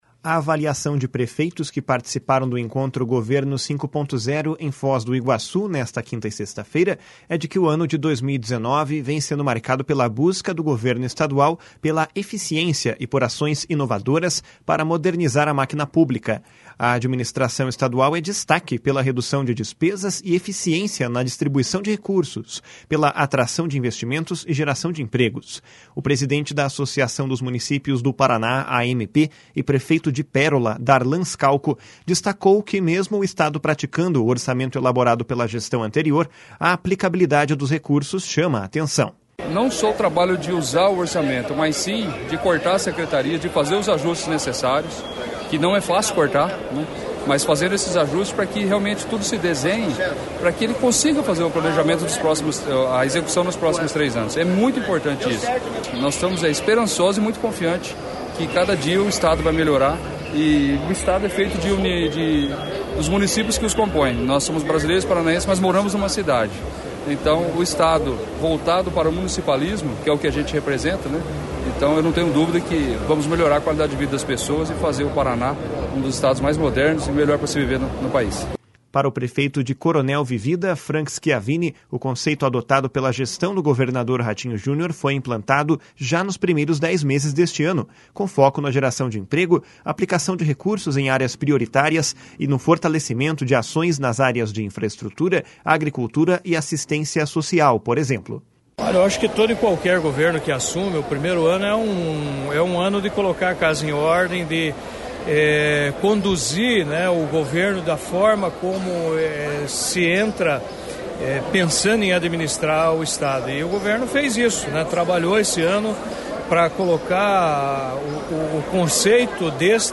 // SONORA DARLAN SCALCO //
Aos prefeitos, o governador Carlos Massa Ratinho Junior garantiu que o Estado está reorganizando o orçamento para fortalecer e potencializar as ações regionais. // SONORA RATINHO JUNIOR //